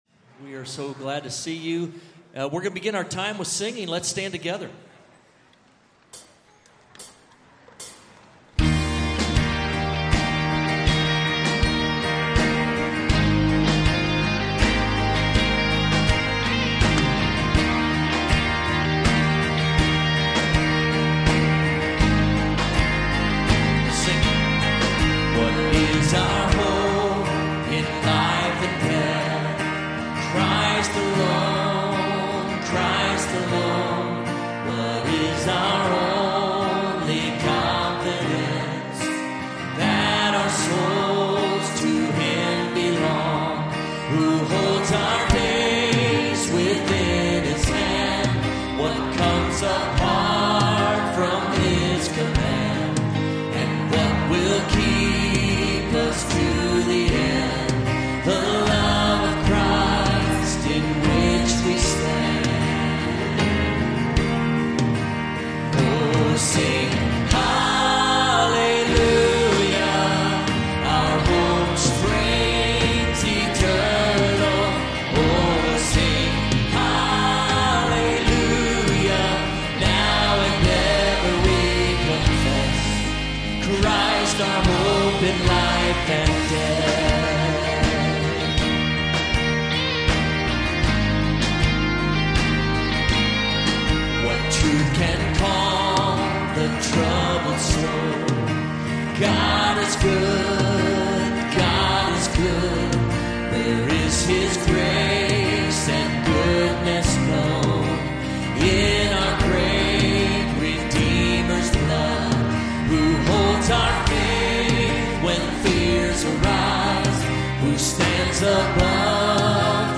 Evening Baptism Service